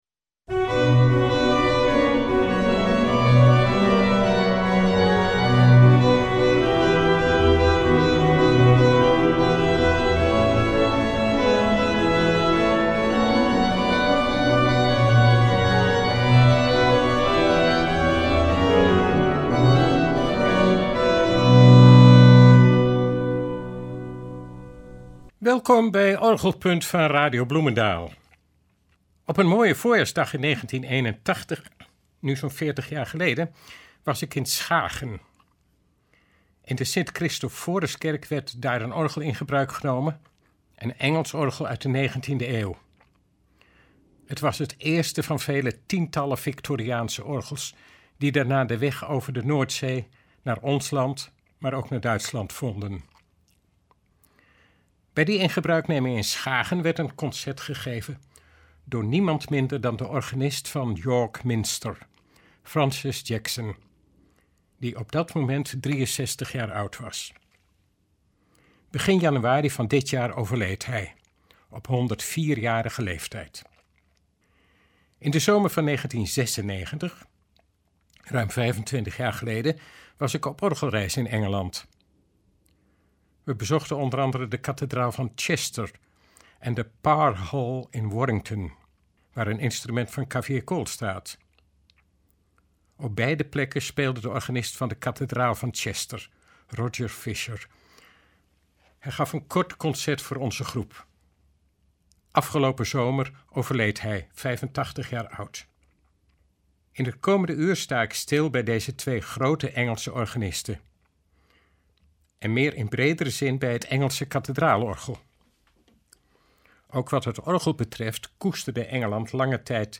Deze vaak grote orgels hebben een enorm dynamisch bereik, van uiterst zacht tot imposant luid. Hun belangrijkste functie is de ondersteuning van de anglicaanse liturgie en haar rijke koorcultuur.
Daarom klinken in de uitzending de orgels van de kathedraal van Chester en van York Minster , bovendien van het betrekkelijk kleine orgel van Castle Howard.